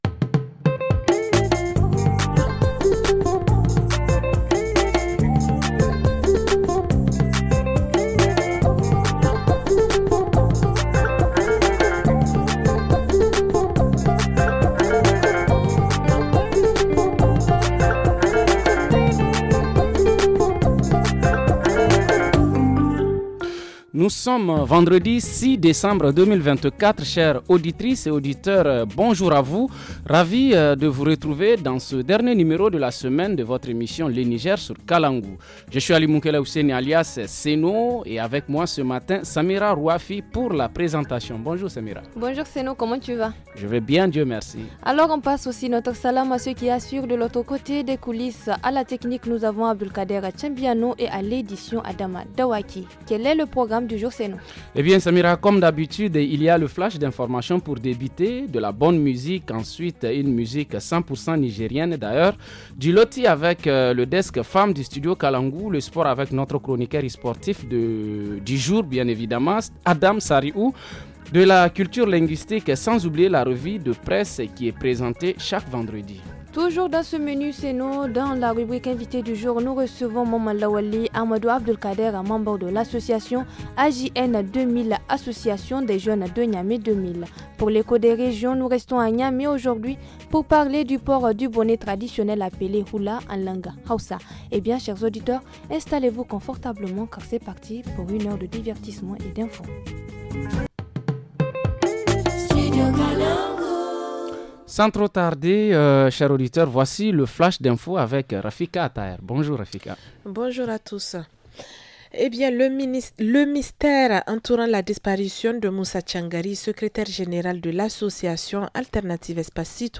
Dans la rubrique hebdo, nous allons découvrir les activés du groupement féminin Babban Sarari Katohu à Zinder. En reportage région, le port du bonnet appelé « hulla » enlangue haoussa chez les jeunes à Niamey.